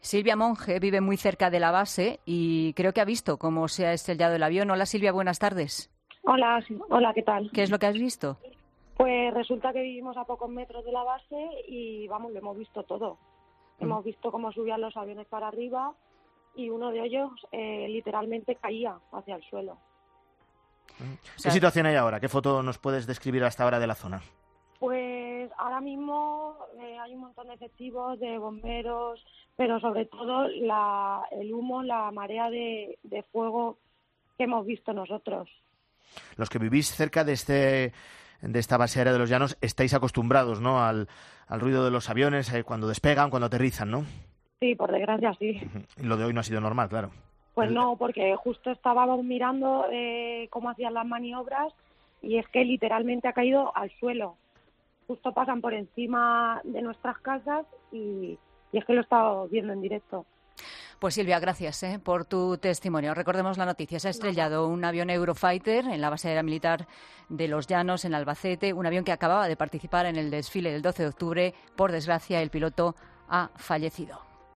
Testigos del accidente de avión del Ejército del Aire en Albacete relatan cómo el piloto no pudo saltar y falleció